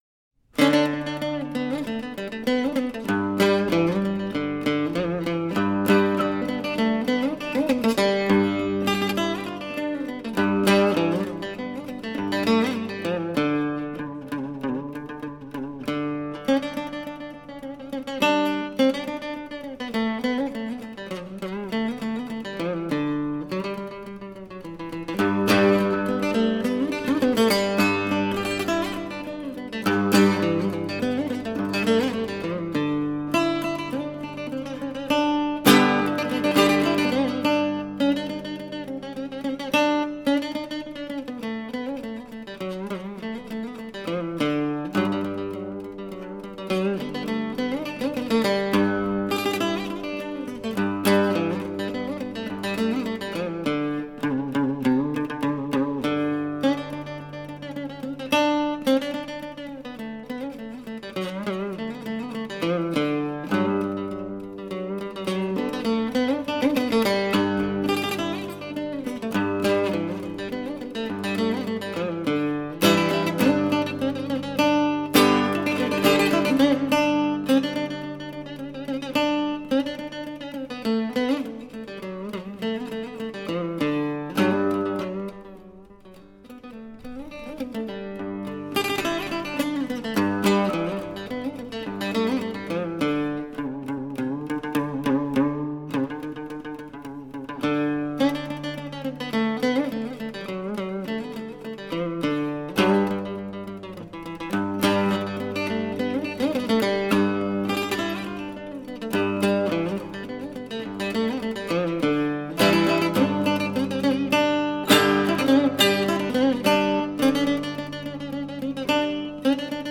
每一首乐曲的旋律均十二分动听
中国民族管弦乐及小品